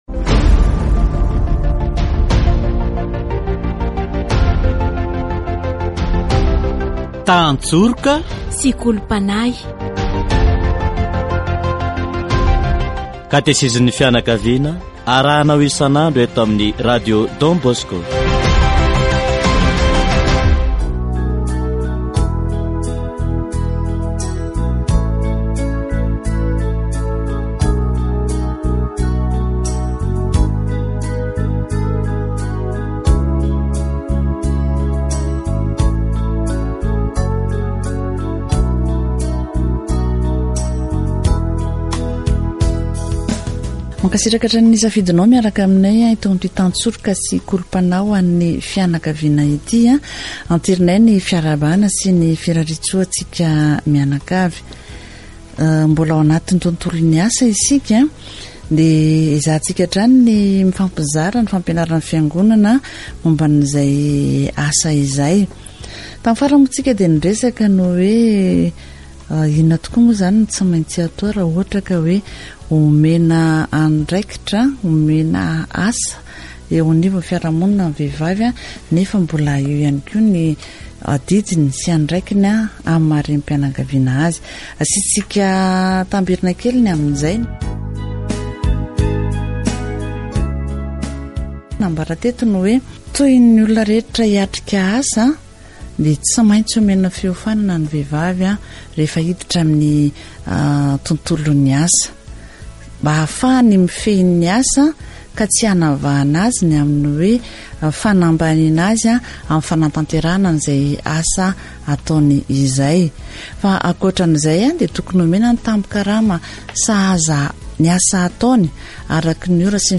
Les enfants ne devraient pas travailler dans les usines avant d’être majeurs. Catéchèse sur le travail